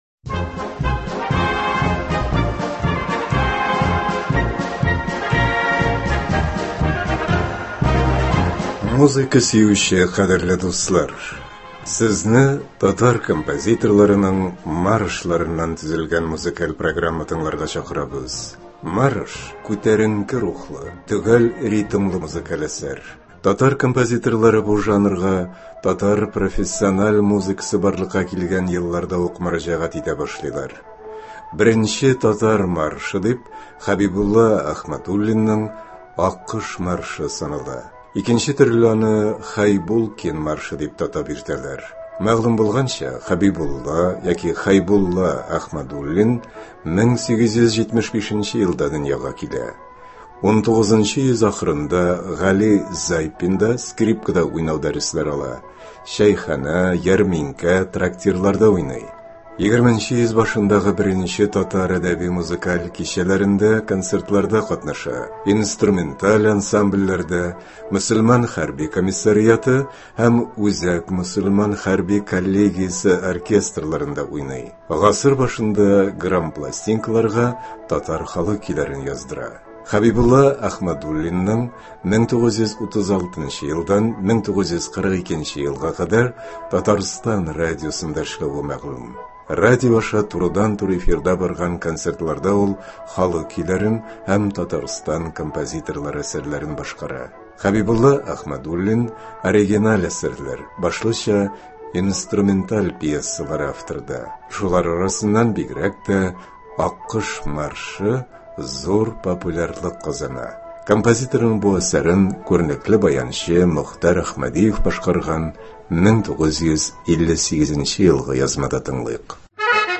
Татар композиторларының маршлары.